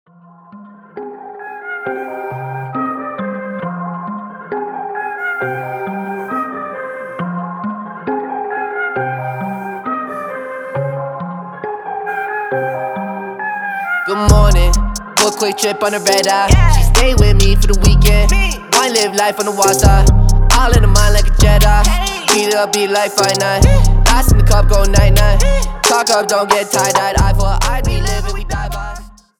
• Качество: 320, Stereo
громкие
Хип-хоп
веселые